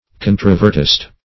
Search Result for " controvertist" : The Collaborative International Dictionary of English v.0.48: Controvertist \Con"tro*ver`tist\, n. One skilled in or given to controversy; a controversialist.